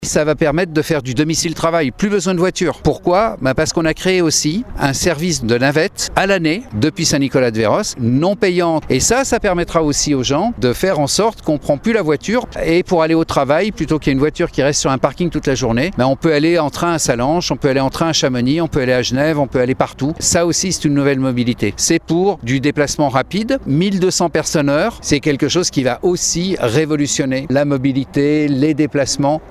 Il existe même un service de navettes, comme l’explique Jean-Marc Peillex le maire de Saint-Gervais.